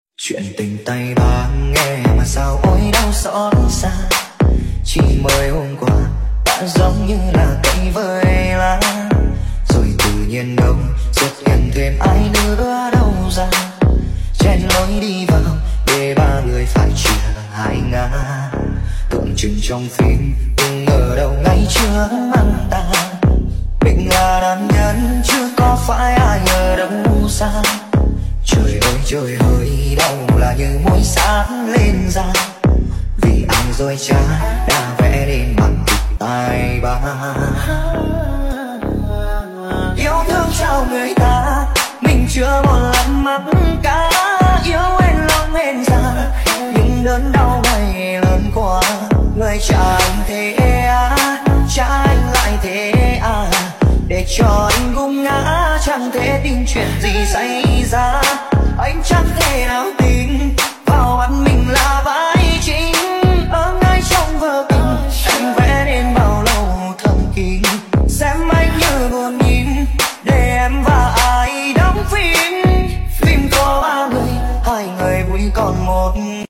Bài hát thiếu nhi tiếng Trung